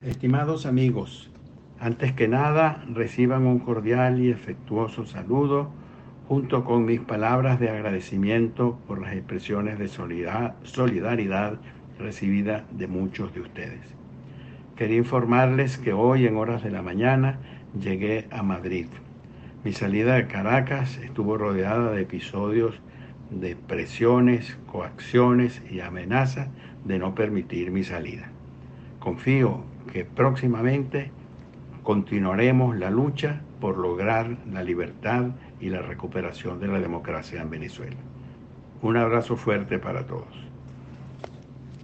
Tras su arribo, El excandidato envió un mensaje de audio a través de su equipo de prensa, en el que denunció que su salida de Caracas estuvo rodeada de “episodios de presiones, coacciones y amenazas” de no permitir su salida y dijo confiar en que próximamente continuaría la lucha por lograr la “libertad y la recuperación de la democracia” en Venezuela.